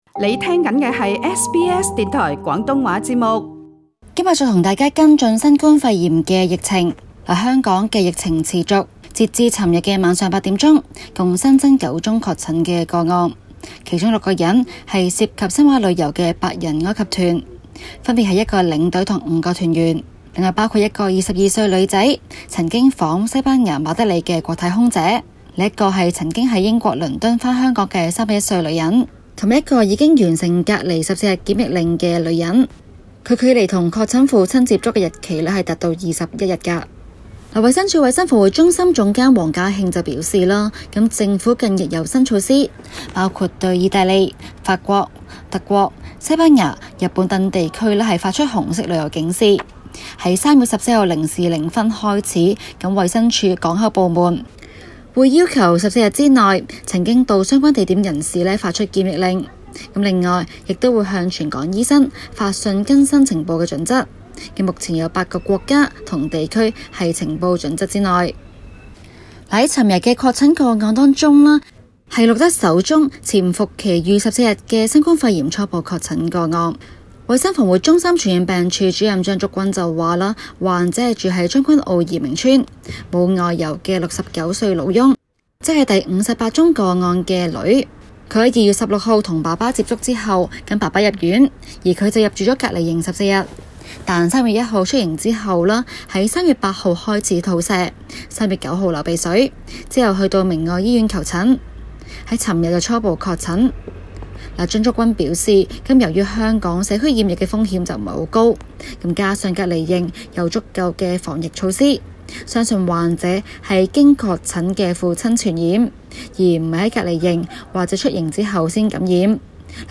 今期【中港快訊】環節報導香港疫情最新進展，再添9宗確診病例，及首次發現有病人帶菌超過21日才發病。